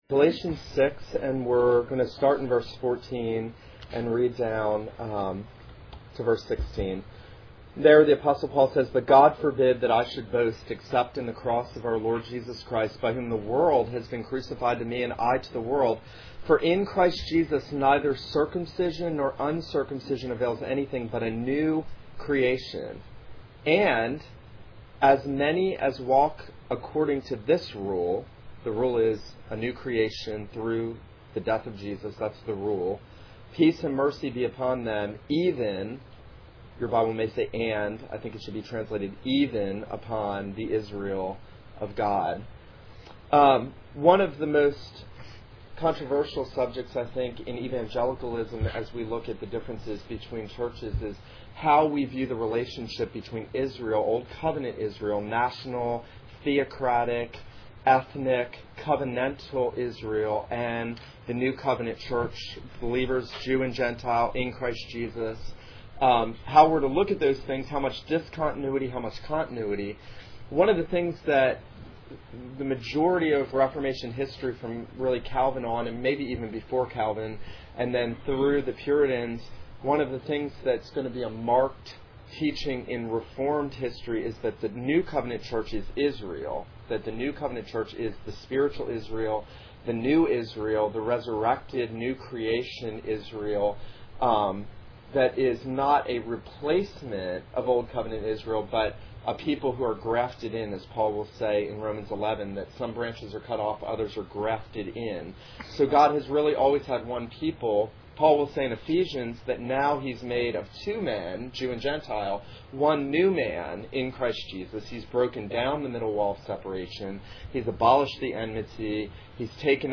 This is a sermon on Galatians 6:14-16.